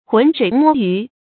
注音：ㄏㄨㄣˊ ㄕㄨㄟˇ ㄇㄛ ㄧㄩˊ
渾水摸魚的讀法